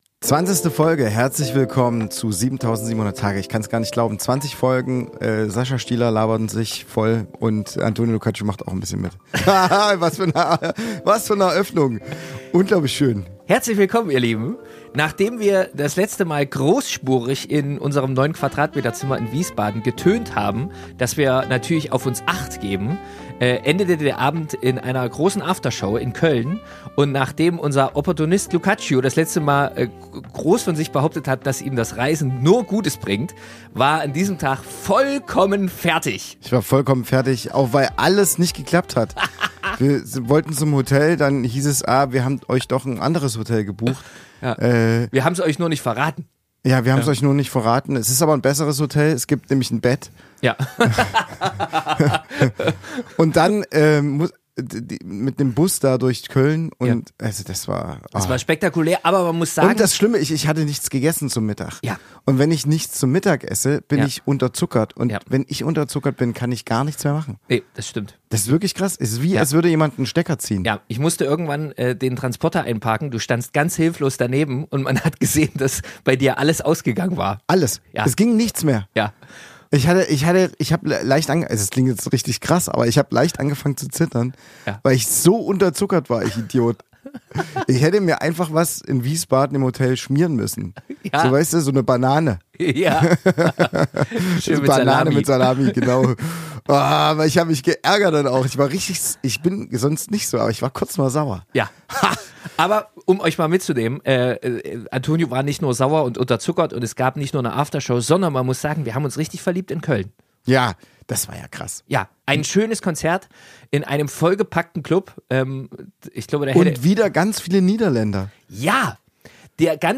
Zwischen Aftershow-Party in Köln und der ersten zarten Regung von Weihnachtsstimmung entfaltet sich ein Gespräch über das große, kleine und herrlich chaotische Leben zweier Diplom-Popularmusiker.